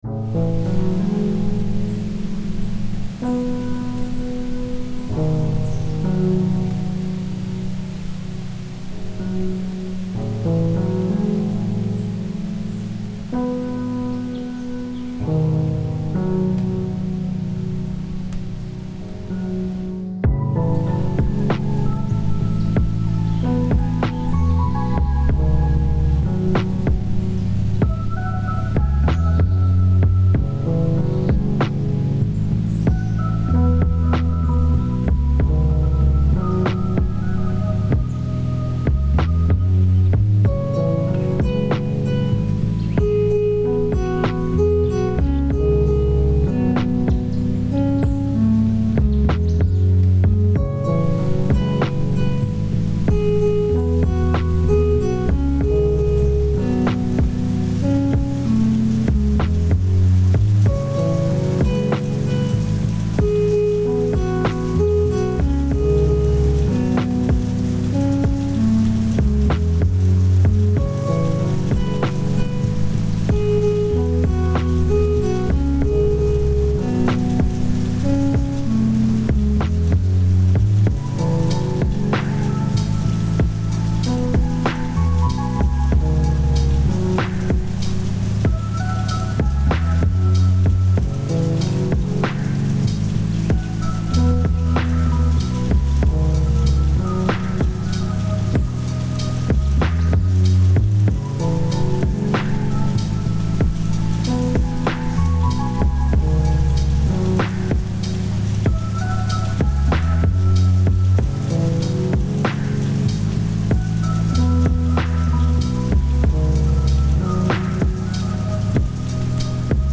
Agradecimientos de fondo musical a